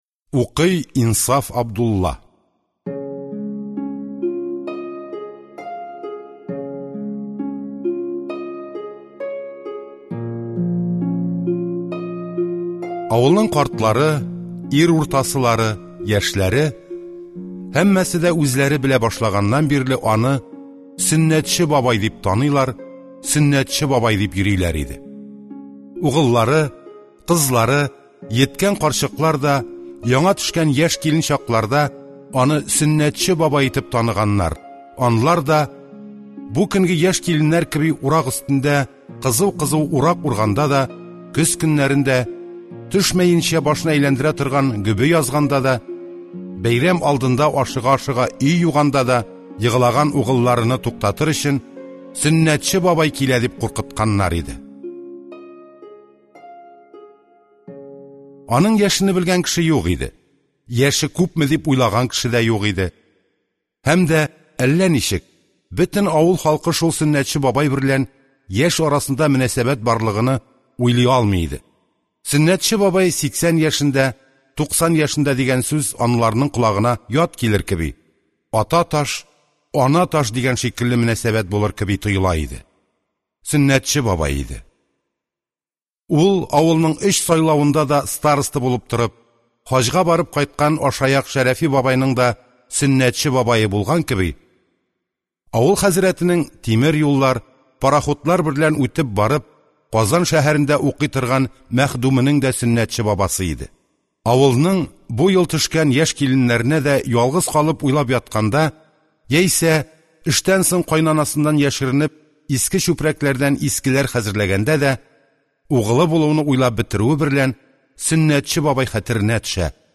Аудиокнига Сөннәтче бабай | Библиотека аудиокниг